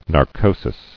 [nar·co·sis]